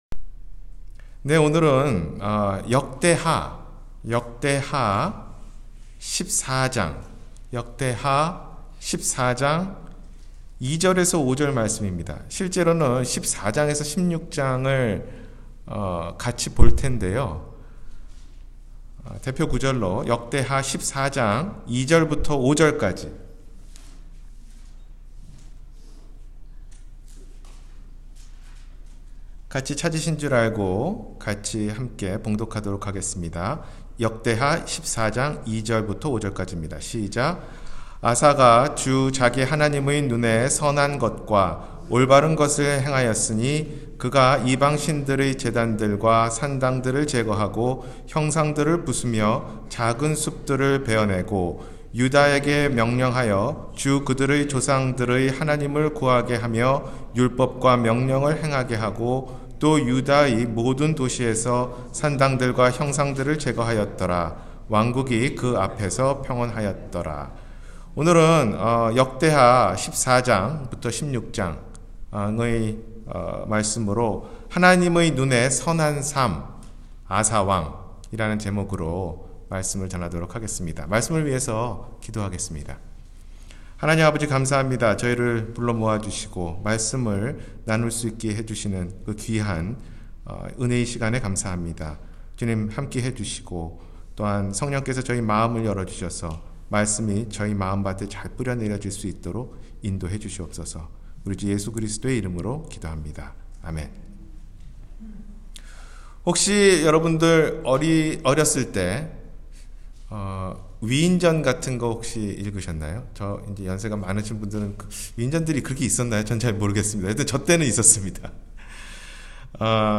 하나님의 눈에 선한 삶-주일설교